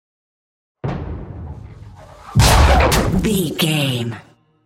Double hit with whoosh shot explosion
Sound Effects
intense
tension
woosh to hit